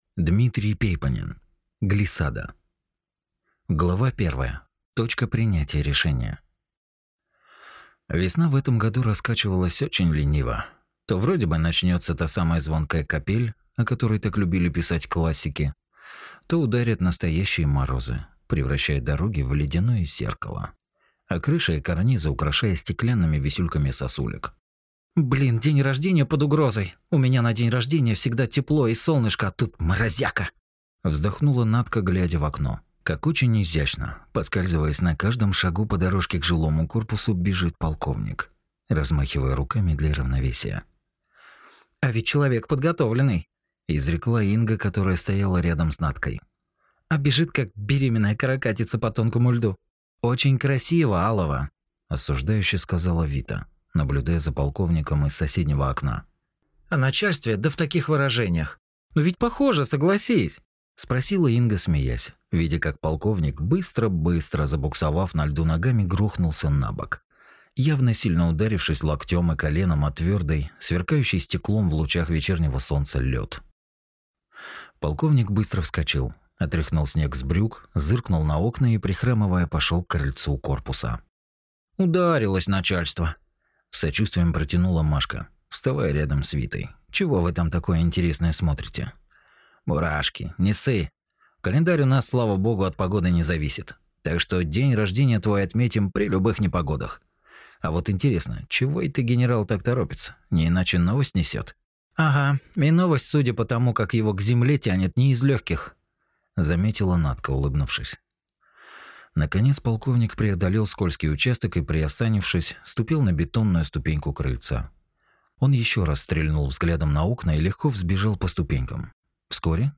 Аудиокнига Глиссада | Библиотека аудиокниг